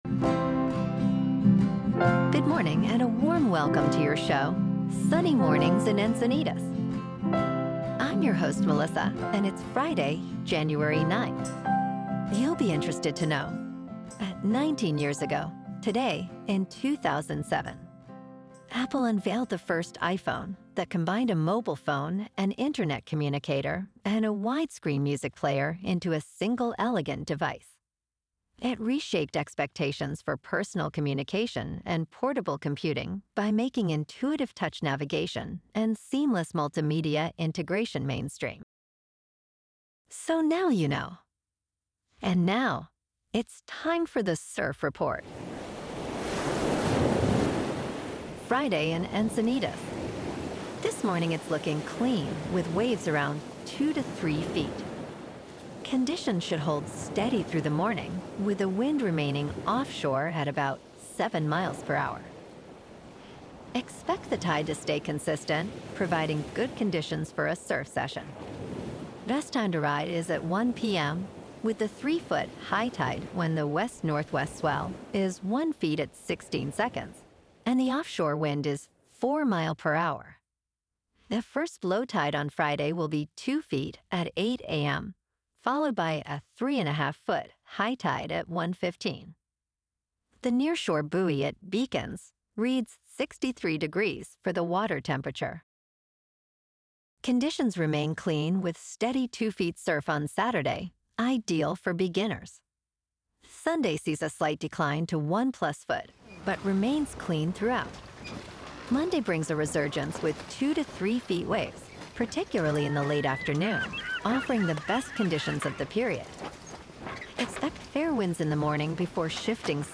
Daily Encinitas News, Weather, Surf, Sports.
The #1 Trusted Source for AI Generated News™